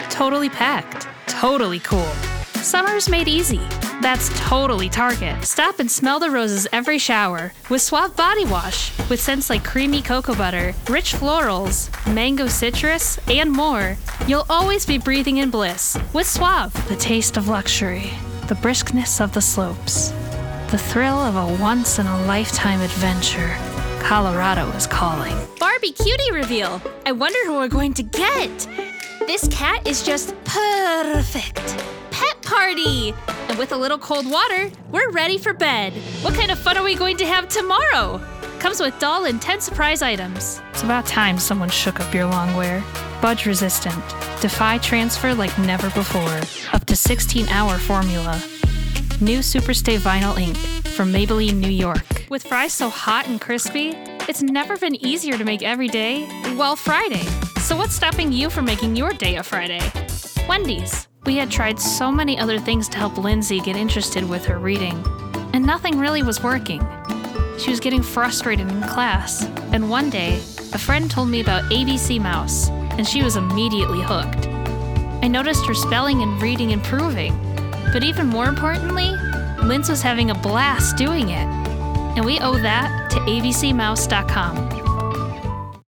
Commercial Demo
American Standard Midwest & Southern
Young Adult